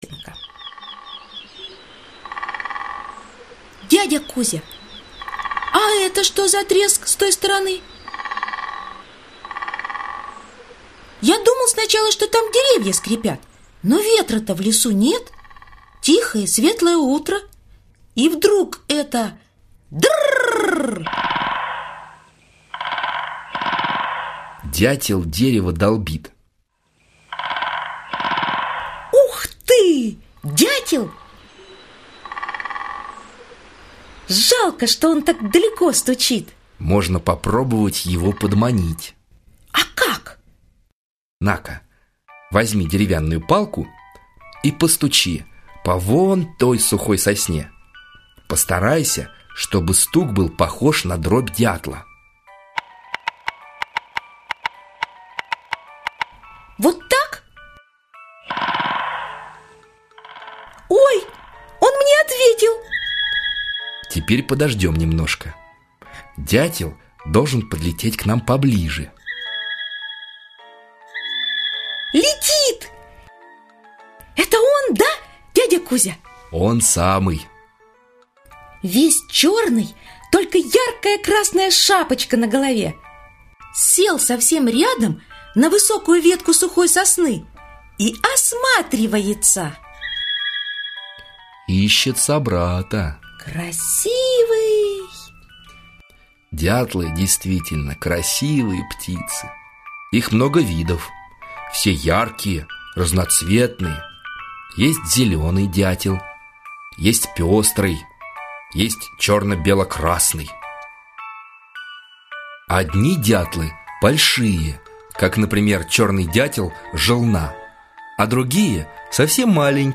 Аудиокнига Лесные птицы | Библиотека аудиокниг
Aудиокнига Лесные птицы Автор Детское издательство Елена.